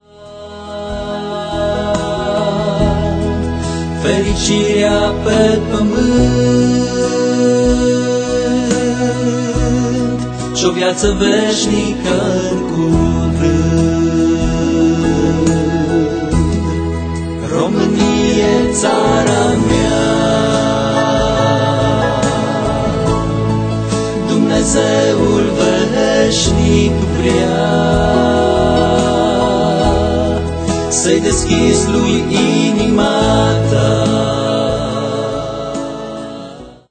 aranjamentul instrumental